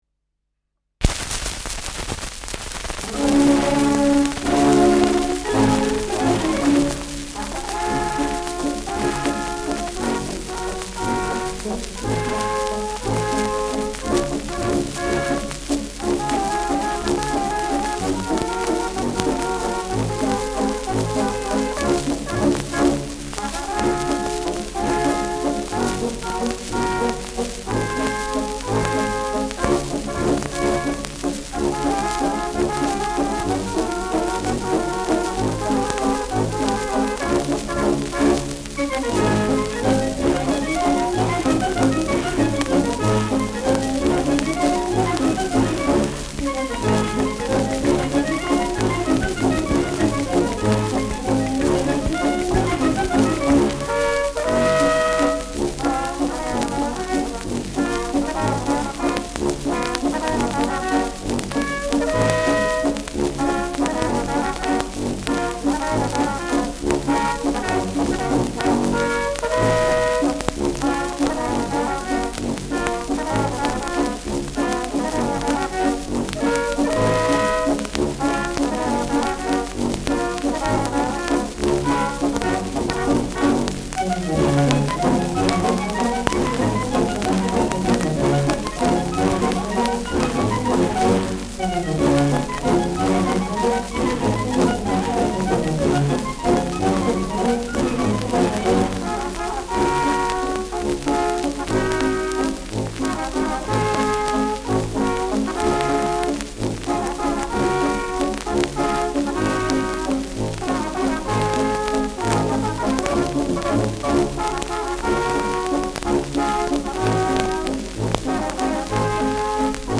Schellackplatte
präsentes Rauschen
Mit Ausruf am Ende.
[Berlin] (Aufnahmeort)